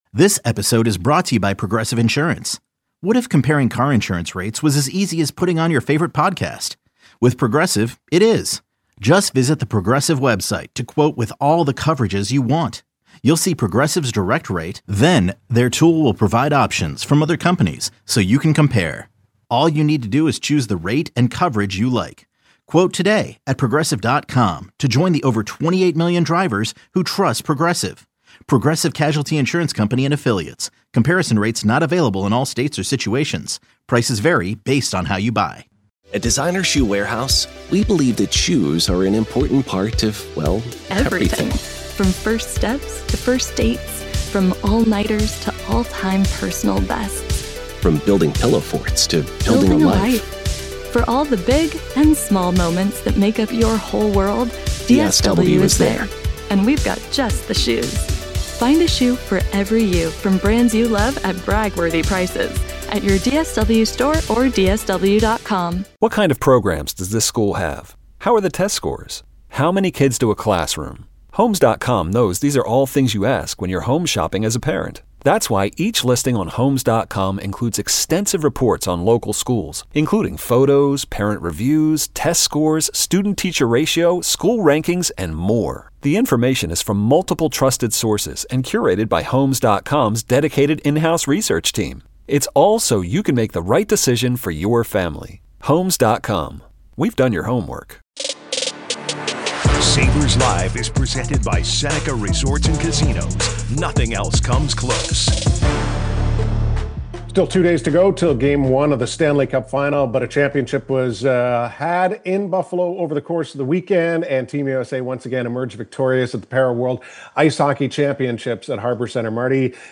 Heard daily from 12PM-1PM on WGR and simulcast on MSG TV, Sabres Live goes deep into the corners everyday, breaking down the play and the players of every Sabres game. Plus, we take listeners around the NHL getting the inside scoop on all the top stories.